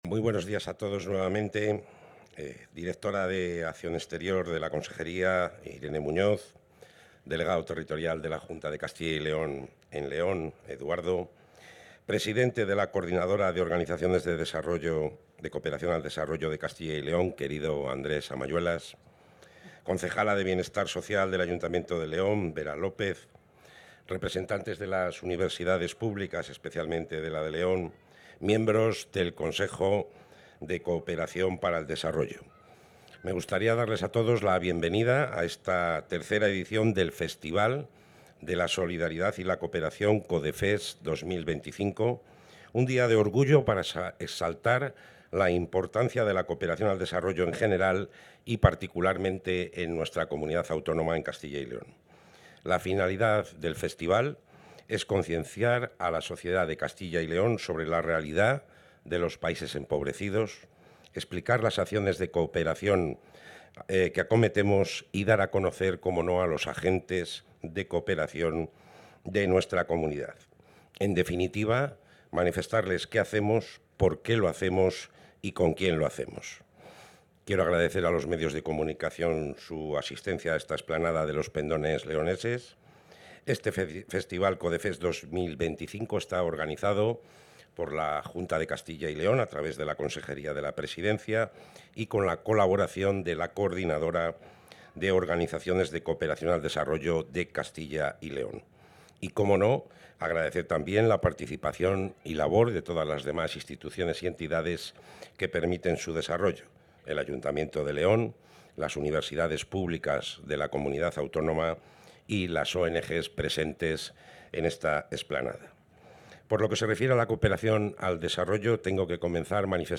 Intervención del consejero.
El consejero de la Presidencia, Luis Miguel González Gago, ha inaugurado la tercera edición del festival COODEFEST Castilla y León Solidaria, que en esta ocasión se celebra en la ciudad de León. En este evento, más de 20 ONGs presentan a los visitantes sus proyectos internacionales destinados a combatir la pobreza y mejorar las condiciones de vida en distintos rincones del mundo.